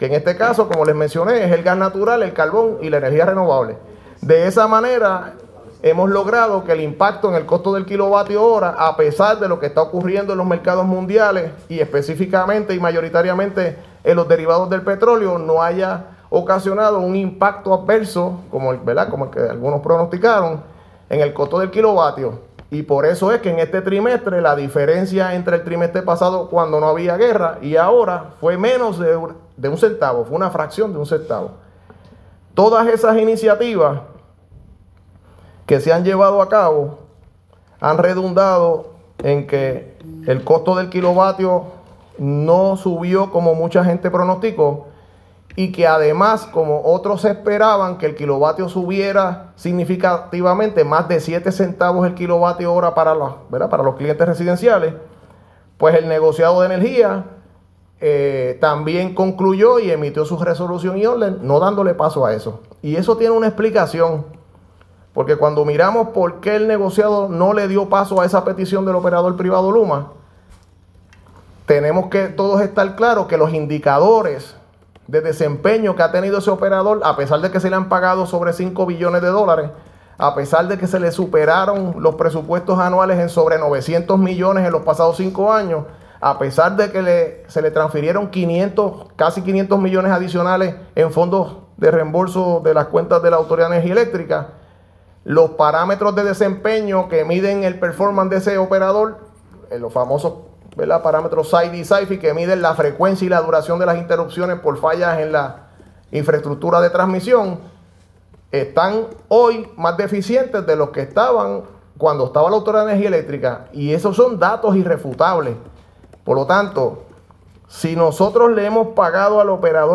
Hoy “En Récord”, el secretario de Asuntos Públicos, Jean Peña Payano junto al Zar de Energía, el ingeniero Josué Colón brindaron una actualización sobre este anuncio.